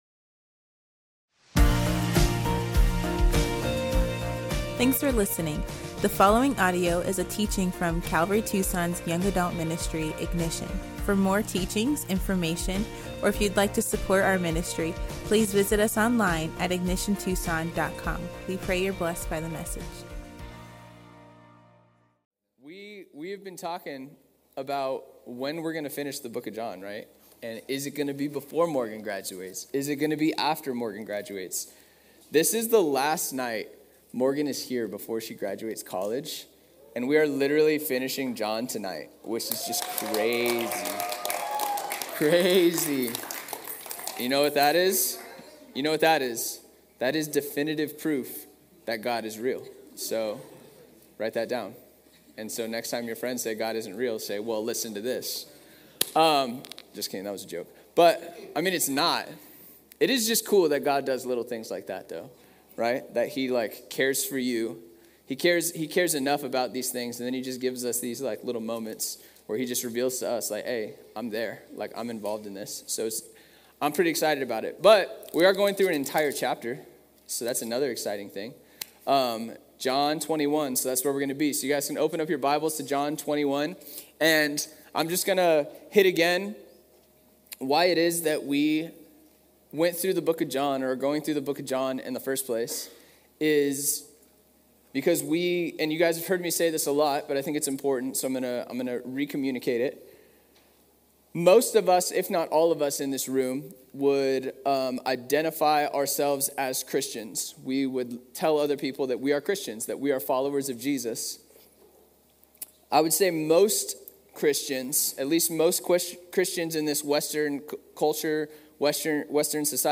Jesus and the Redemption of Peter" at Ignition Young Adults on May 09